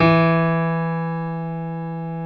55p-pno15-E2.wav